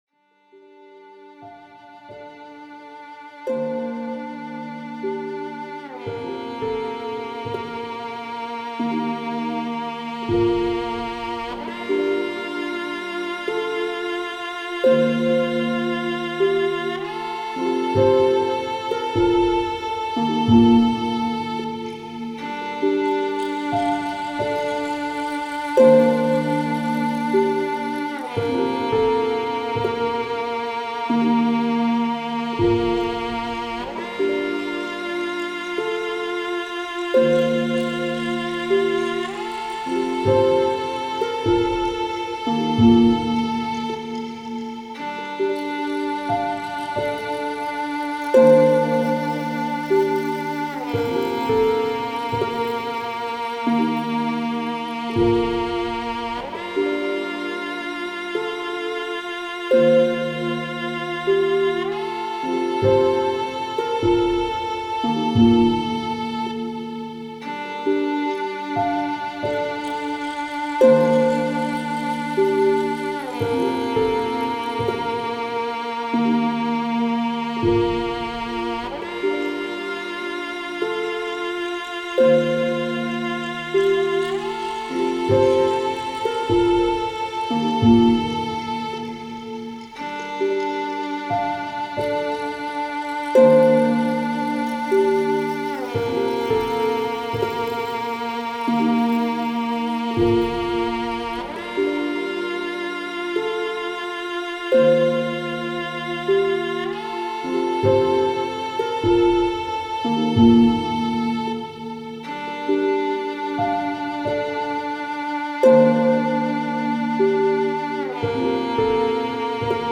Genre: Downtempo, New Age, Ambient.